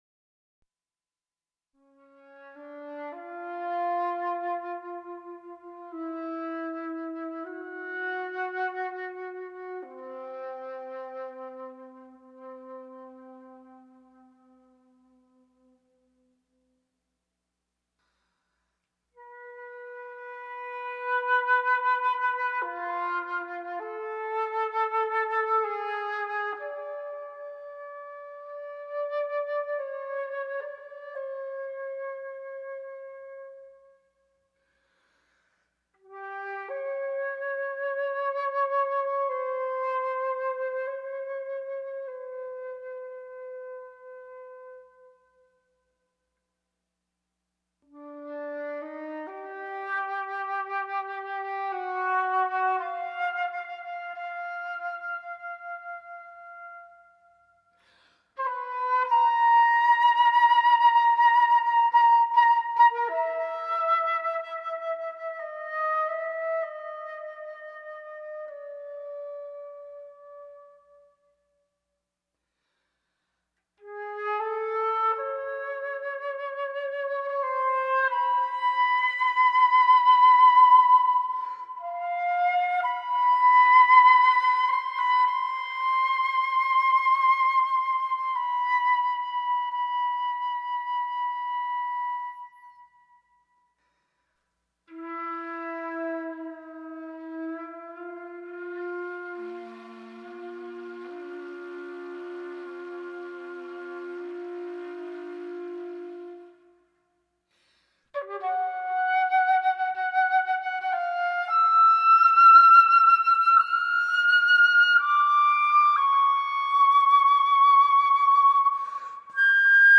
Instrumentación: Flauta Sola